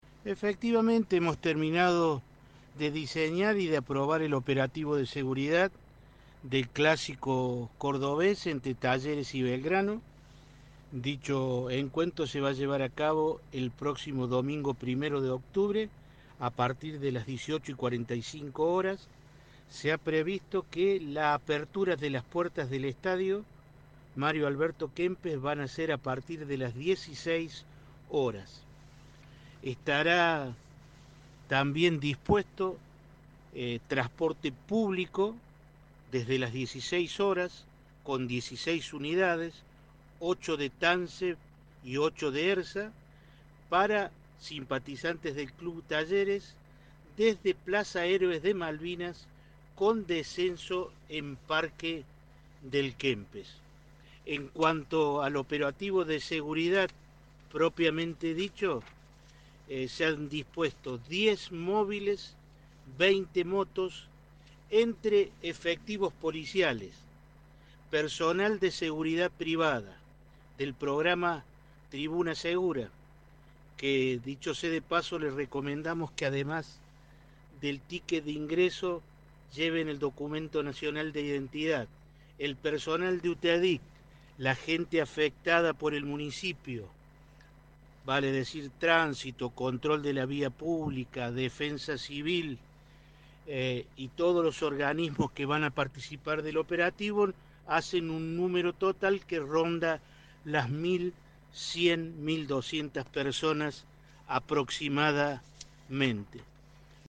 Audio Marcelo Frosasco, titular del Cosedepro